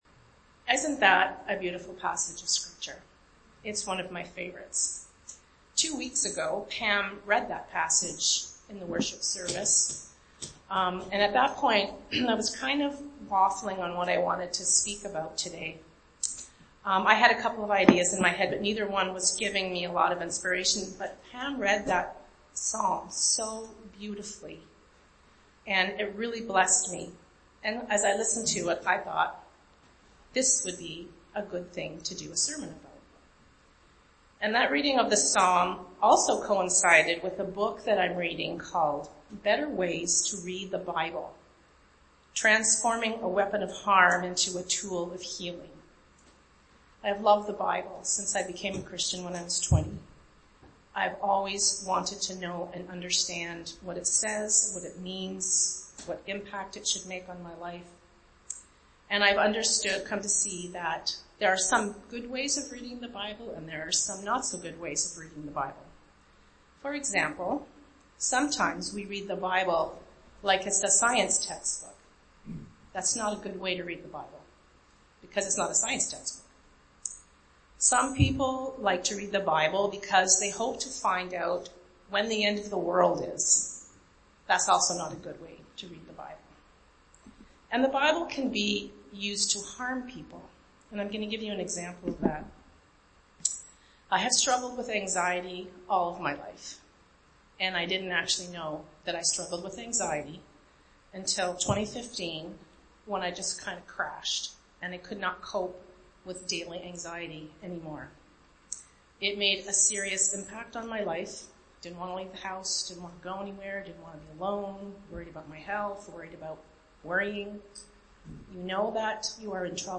Archived Sermons